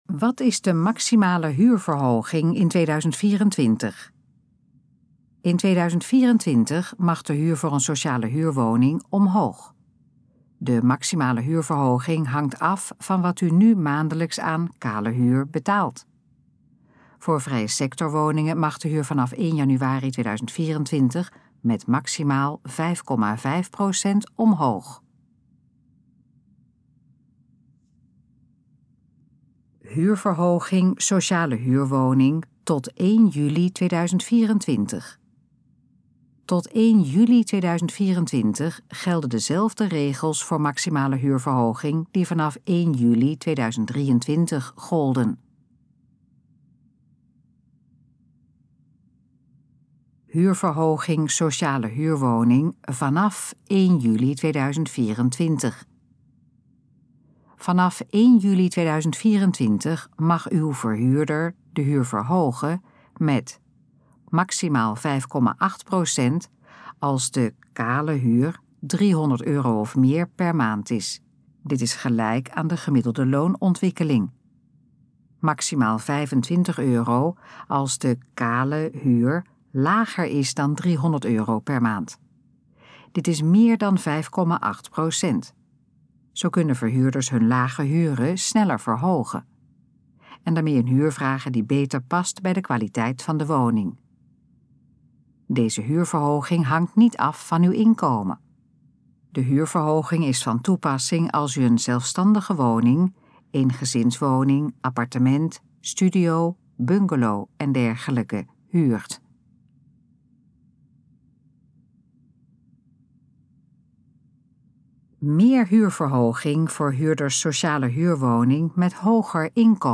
Gesproken versie van: Wat is de maximale huurverhoging in 2024?
Dit geluidsfragment is de gesproken versie van de pagina Wat is de maximale huurverhoging in 2024?